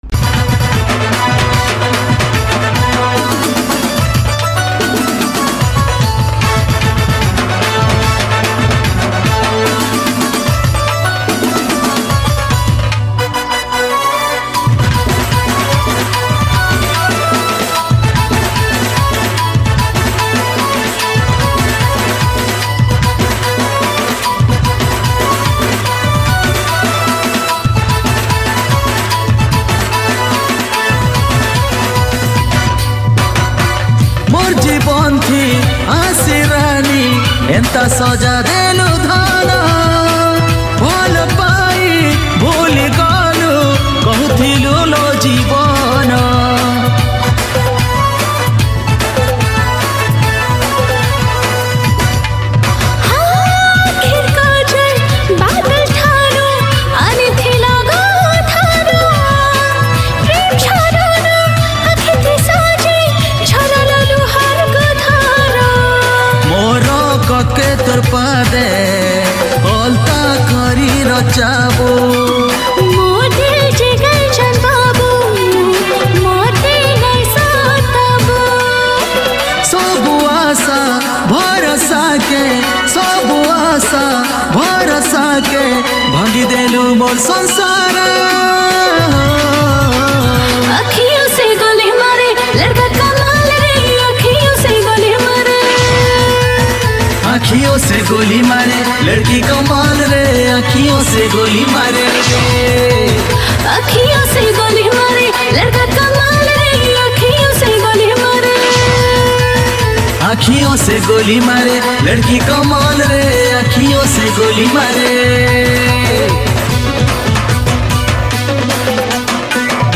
Sambalpuri Song